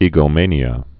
(ēgō-mānē-ə, -mānyə)